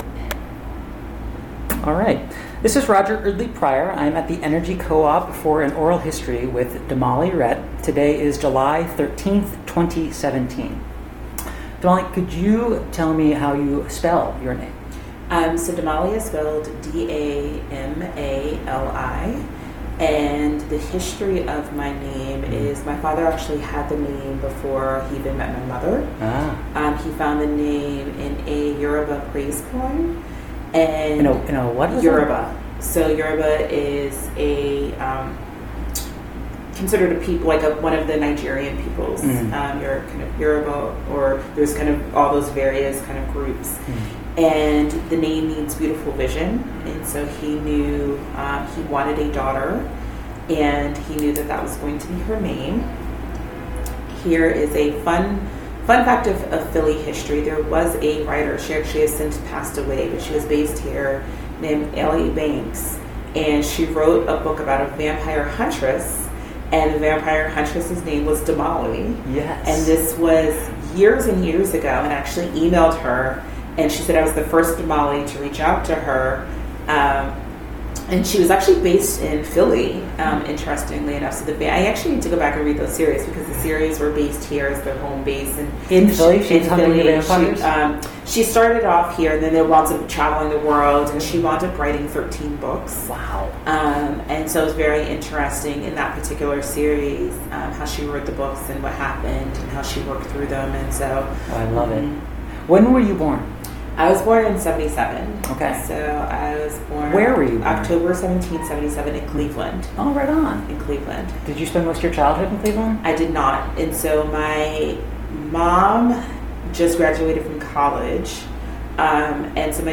Oral histories
Place of interview Pennsylvania--Philadelphia